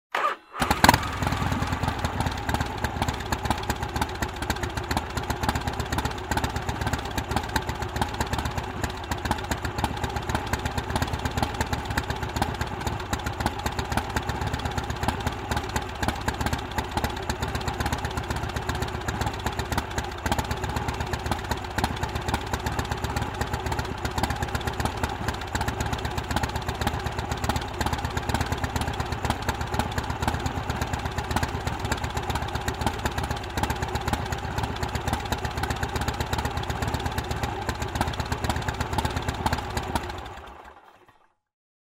Звуки мопеда, скутера
Звук запуска двигателя Harley-Davidson длительная работа и выключение зажигания